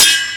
sword_combat